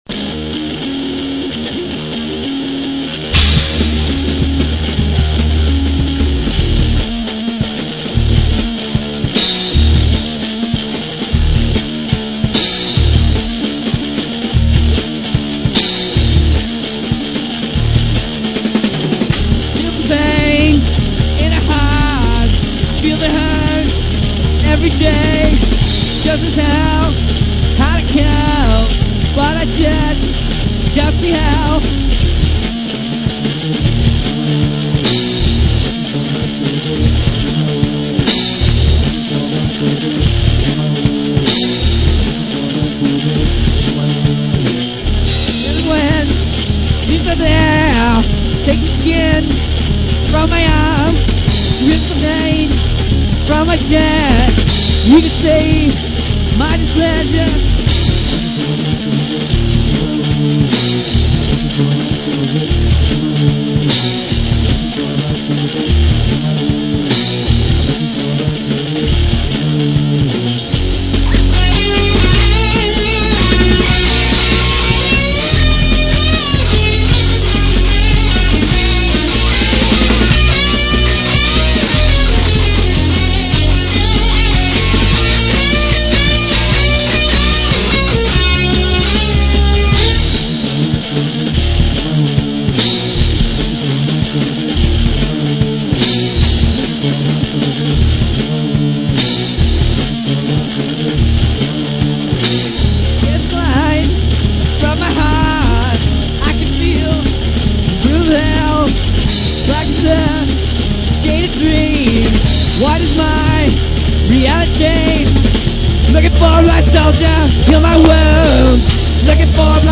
Whiny voice.. very whiny, but good ***